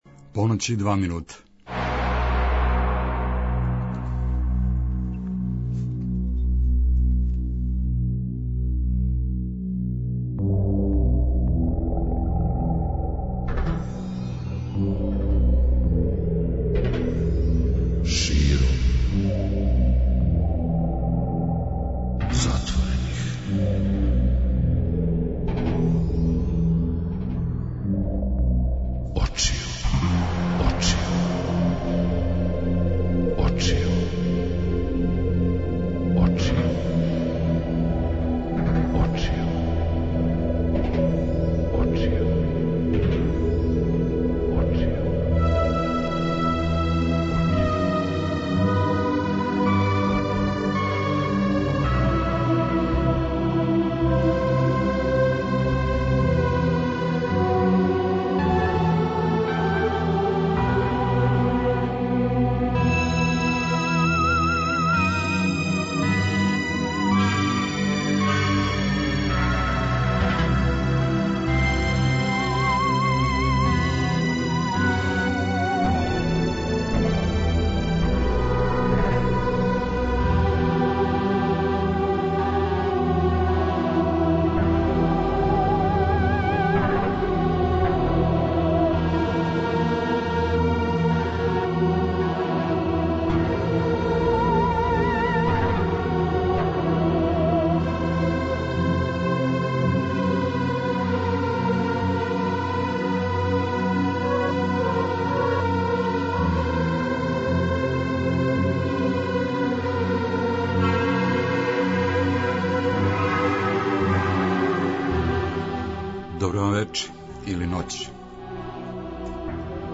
Средом од поноћи - спој добре рок музике, спортског узбуђења и навијачких страсти.